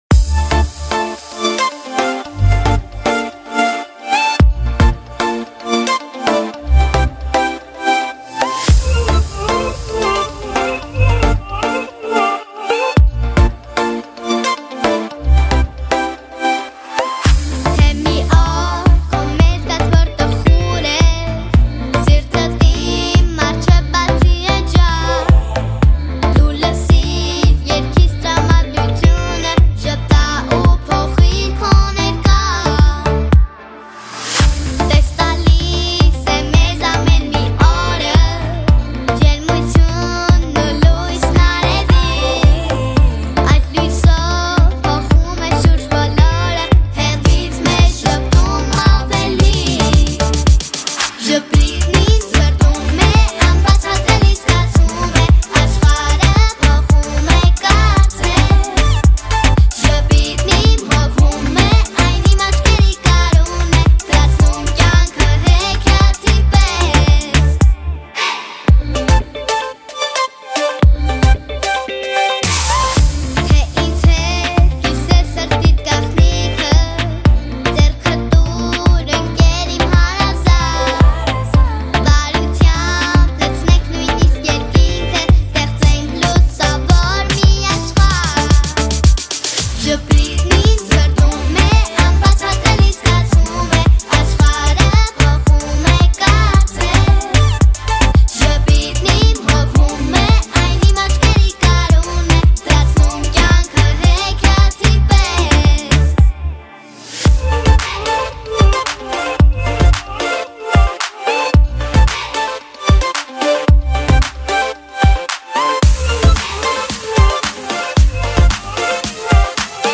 Армянская музыка